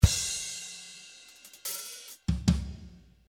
74 BPM - White Dress (37 variations)
Lookin for fat toms ? great slow rock drum loops.
37 variations of great slow rock drum loops.
With a lot of toms,ride and big snare.